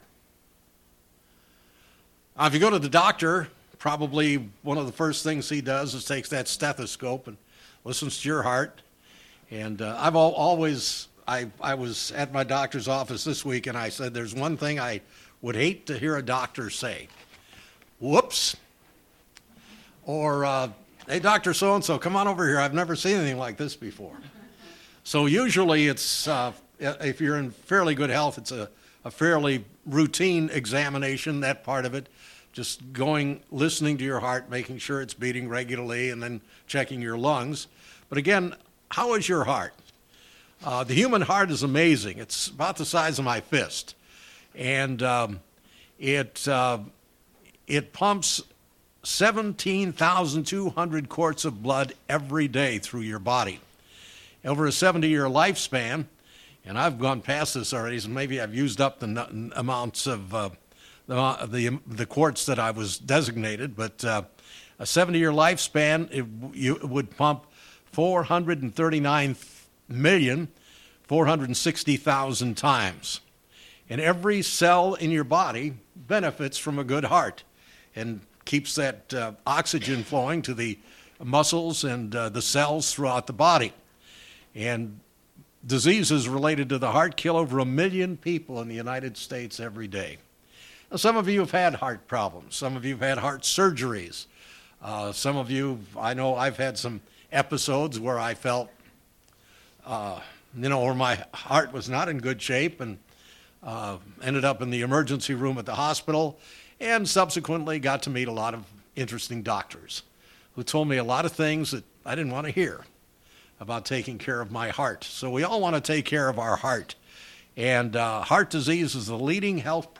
As Christians, we also have an important spiritual part of our life, our spiritual heart. In this sermon, the speaker uses analogies of the human heart to express concepts of our spiritual heart.